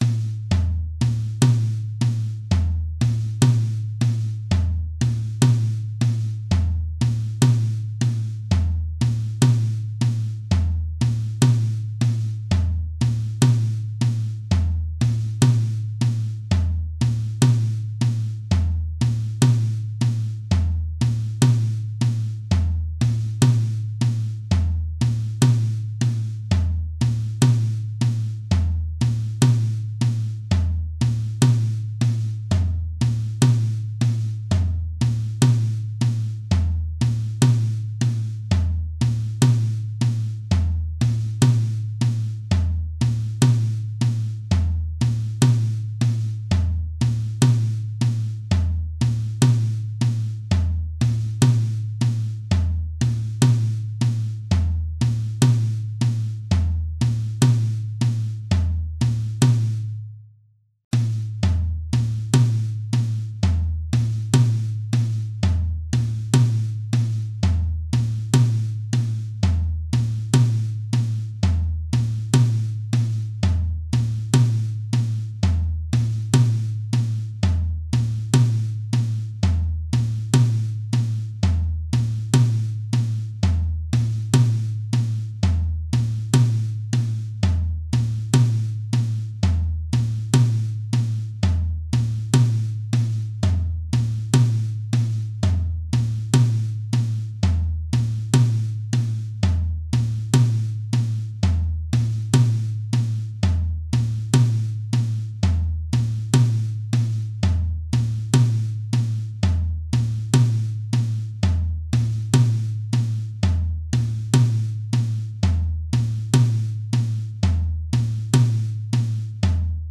orchestra_percussion_120s.mp3